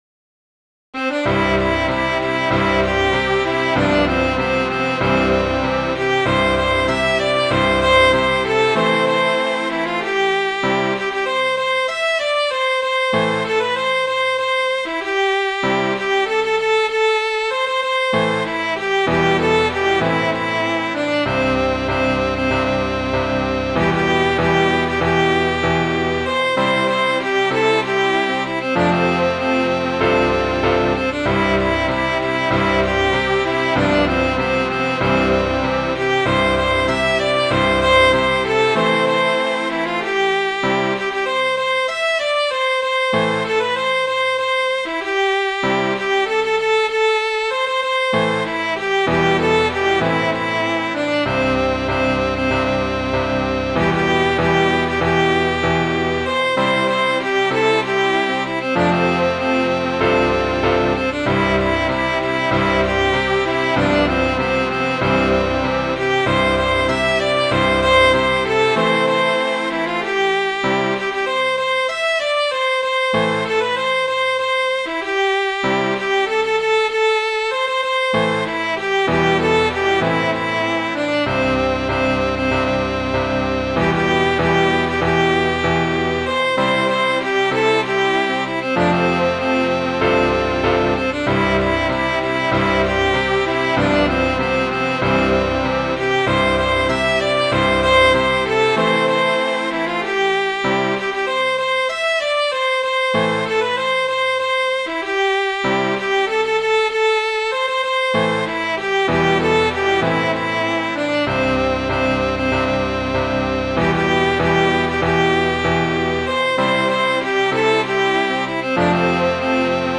brennan.mid.ogg